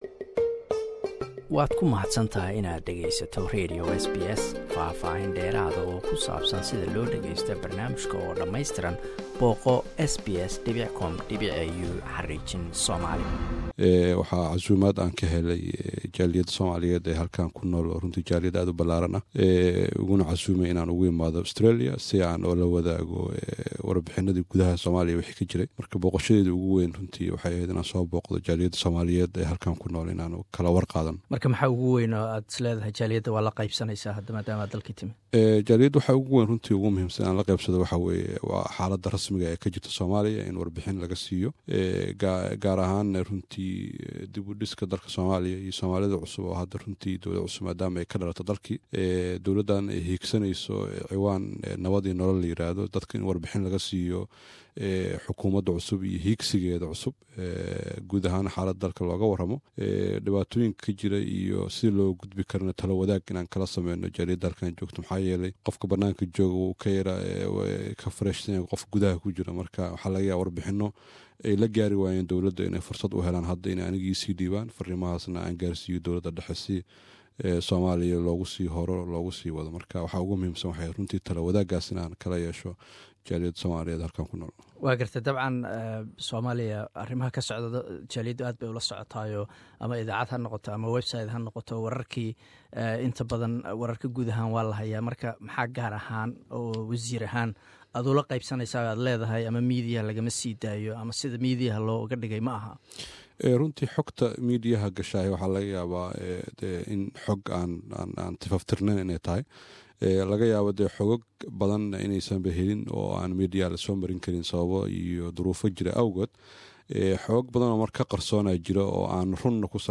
Waraysi: Wasiir Cabdirahman Kulmiye Xirsi
Cabdirahman Kulmiye Xirsi waa wasiiru dawlaha gaadiid cirka iyo dhulka ee dawlada federalka Soomaaliya. Wuxuu hadda booqasho ku joogaa Australia, wuxuuna arimo kala duwan uga waramay Laanta Afsoomaaliga ee SBS.